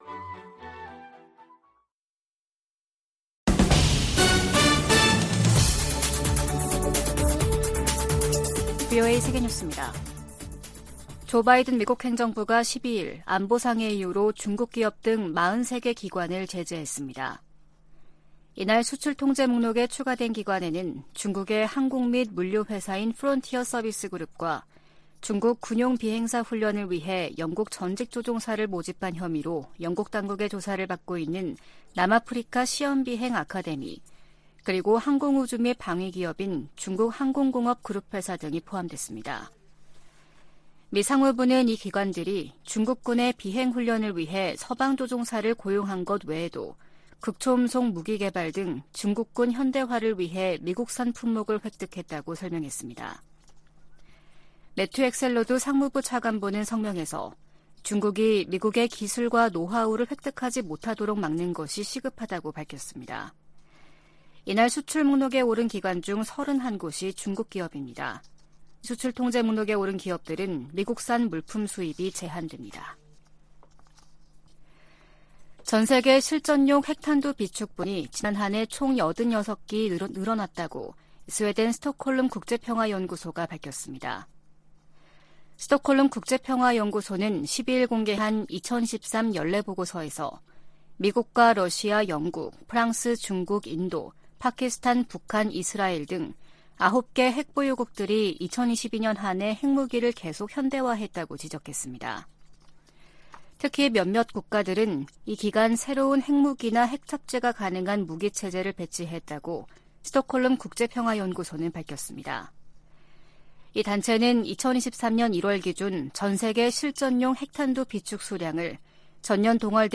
VOA 한국어 아침 뉴스 프로그램 '워싱턴 뉴스 광장' 2023년 6월 13일 방송입니다. 북한 열병식 훈련장에 다시 차량과 병력의 집결 장면이 관측됐습니다. 7월의 열병식 개최가 가능성이 주목되고 있습니다. 국제해사기구(IMO) 회원국들이 사상 첫 북한 미사일 발사 규탄 결의문을 받아들일 수 없다는 북한측 주장을 일축했습니다.